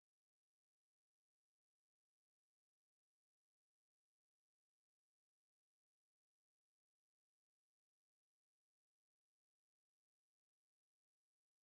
NAS FRASES EM NEGRITO, FAZER COM UMA ENTONAÇÃO BEM PRA CIMA.